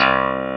CLAV2HRDC2.wav